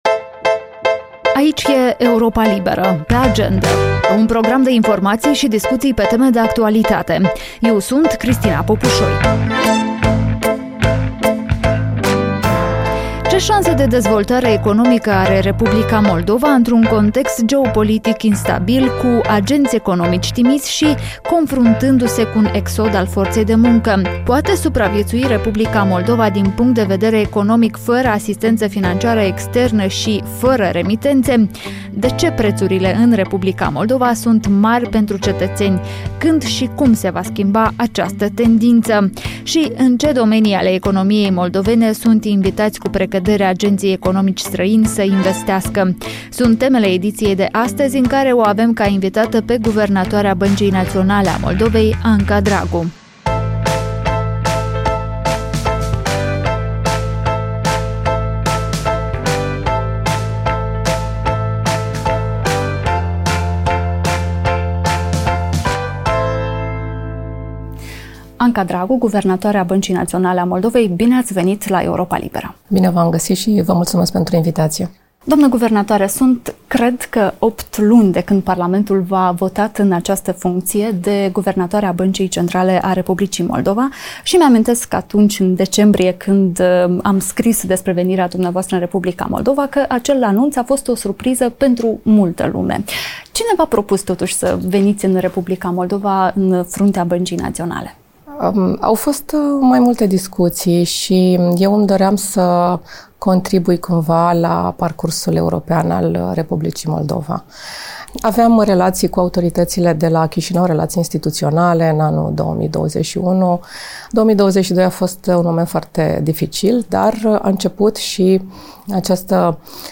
Guvernatoarea Băncii Naționale a R. Moldova, Anca Dragu, vorbește în podcastul „Pe Agendă” despre motivele și contextul venirii sale din România în funcția de guvernatoare a băncii centrale de la Chișinău. Dragu explică și de ce prețurile în R. Moldova par mari pentru cei mai mulți dintre cetățeni.